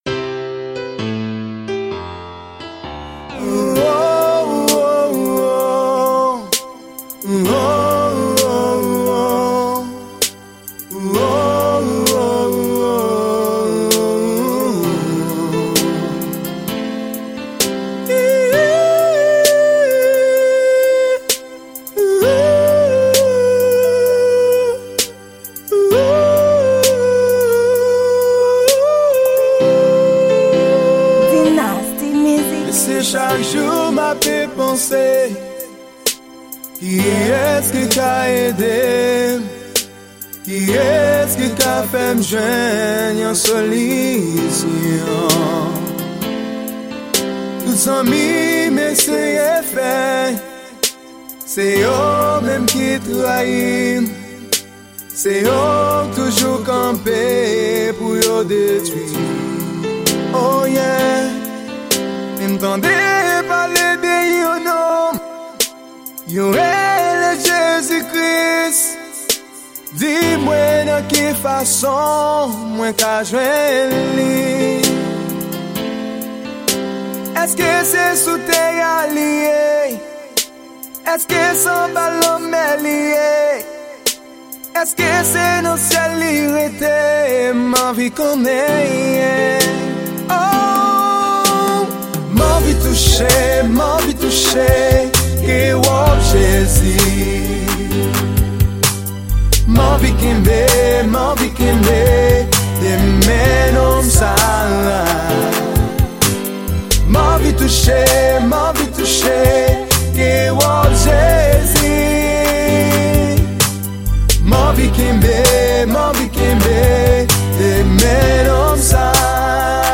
Genre: slow creol.